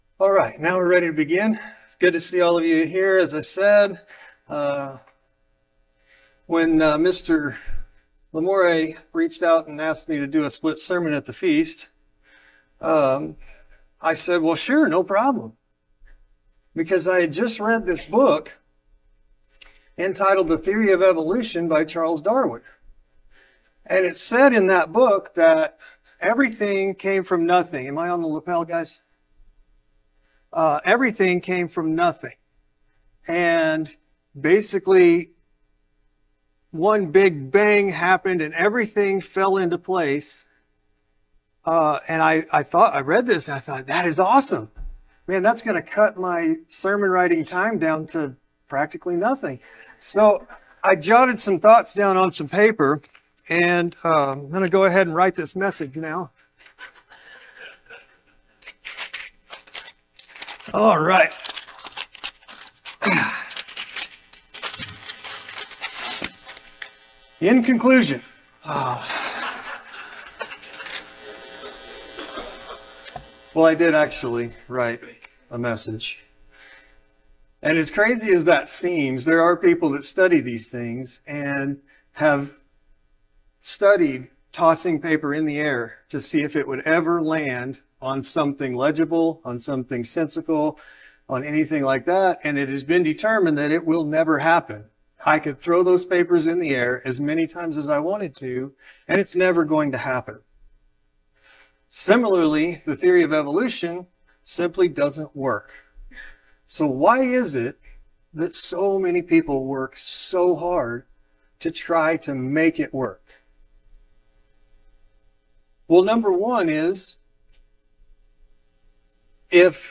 Dive into a captivating exploration of God’s awe-inspiring creation in this engaging sermon, where the speaker humorously debunks the theory of evolution and marvels at the universe’s grandeur—from stars that sing praises through radio telescope recordings to the intricate design of DNA and the human nervous system, crafted with divine precision. With vivid examples like the Whirlpool Galaxy, the massive Canis Majoris star, and the miraculous formation of a baby’s nerve endings, this message, rooted in scriptures like Psalms 139:14 and Revelation 21:1, ignites wonder at God’s creative power and invites believers to embrace their role in building His kingdom, urging them to keep faith strong and God magnified amidst life’s trials.